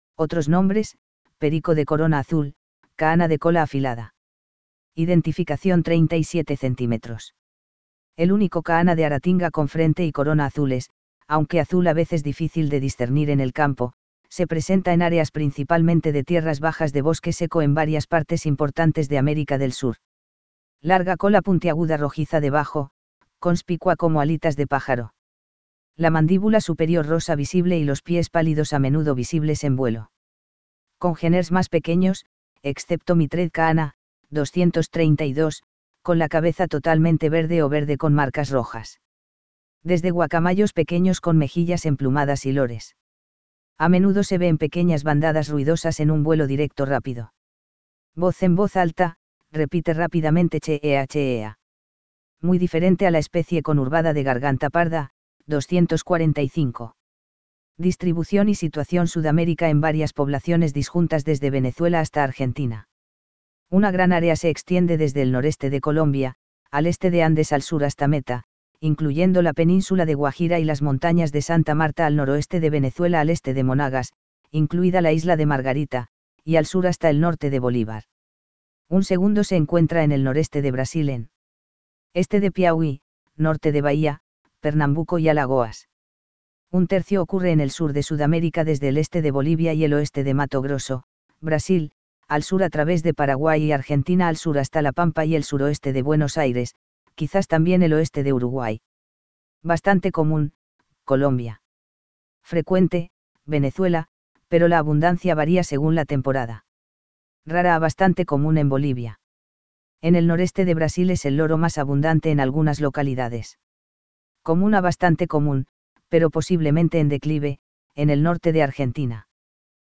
VOZ En voz alta, repite rápidamente cheeah-cheeah.
lorocabeza azul.mp3